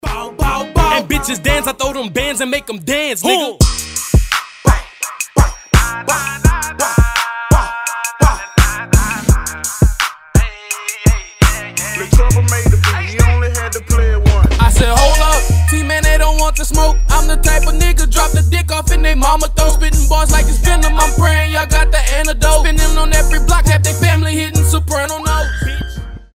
Хип-хоп
качающие
ремиксы